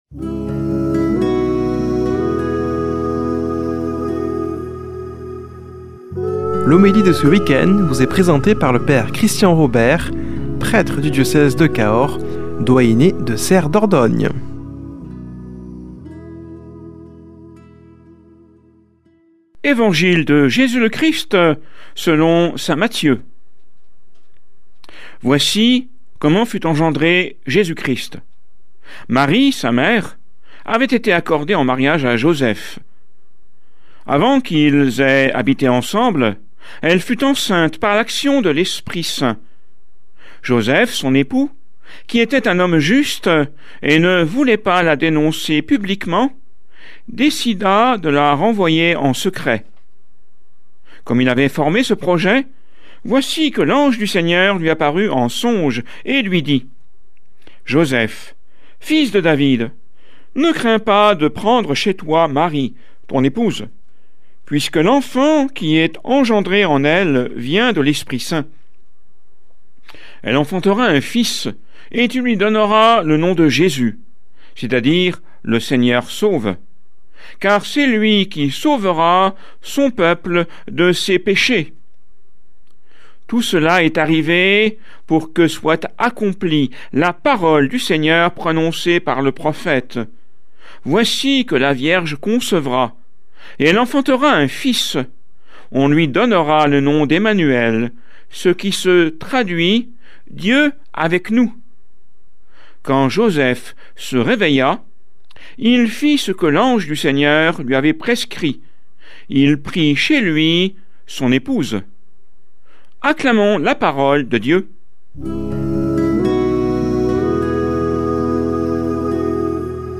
Homélie du 20 déc.